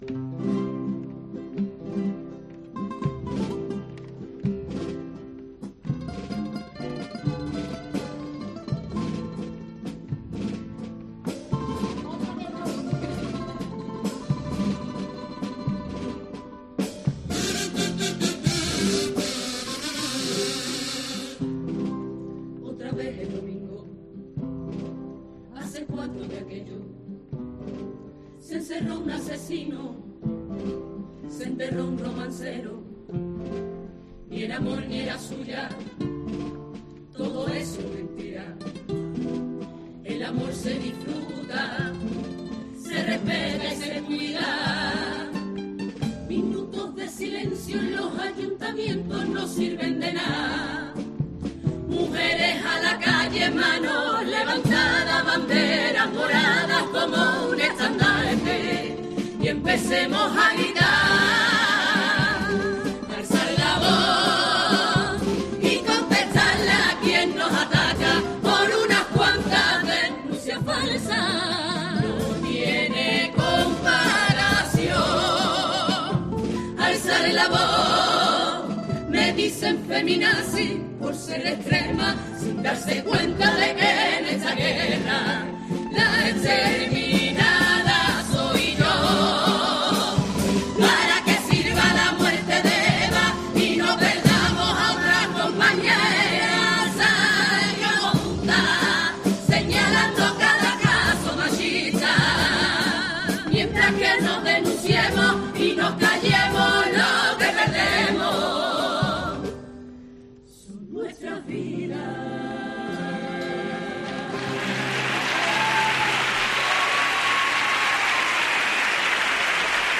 Carnaval